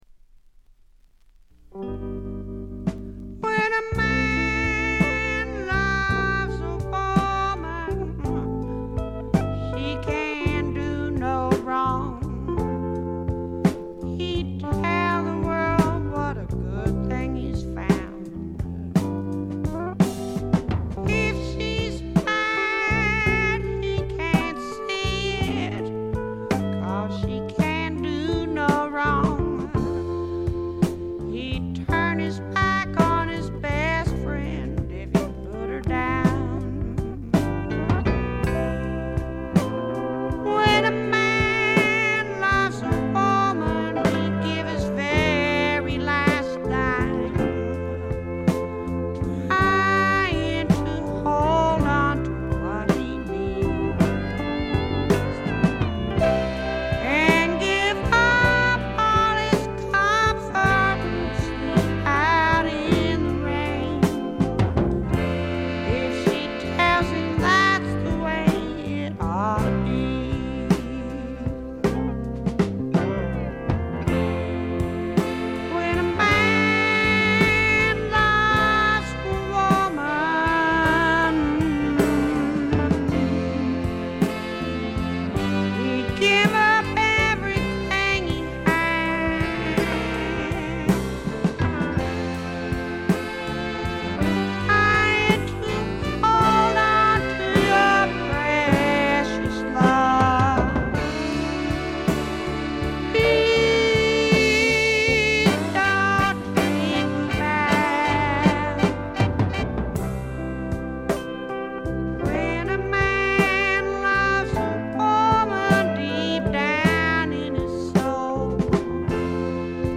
静音部での微細なチリプチが少しだけ。
試聴曲は現品からの取り込み音源です。
Vocals, Banjo, 12 String Guitar